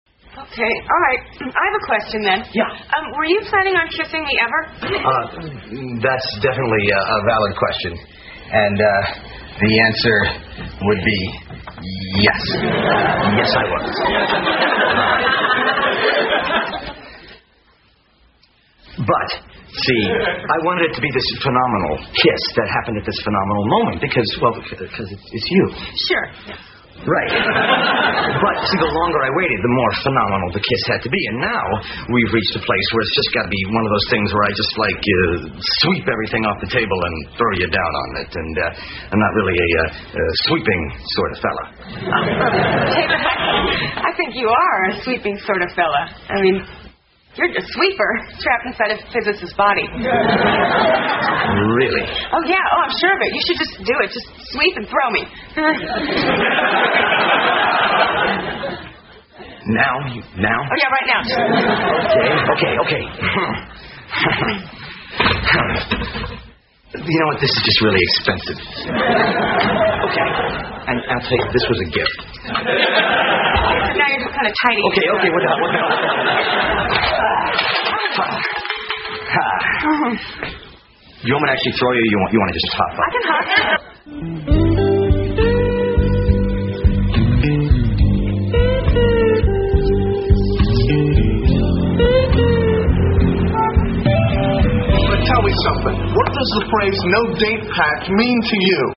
在线英语听力室老友记精校版第1季 第118期:猴子(6)的听力文件下载, 《老友记精校版》是美国乃至全世界最受欢迎的情景喜剧，一共拍摄了10季，以其幽默的对白和与现实生活的贴近吸引了无数的观众，精校版栏目搭配高音质音频与同步双语字幕，是练习提升英语听力水平，积累英语知识的好帮手。